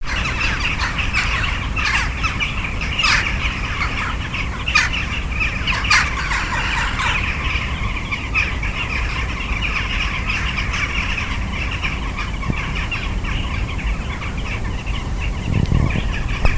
here is what a cacophony of jackdaws sounds like. They're small grey and black corvids that hang out in huge flocks and are not quiet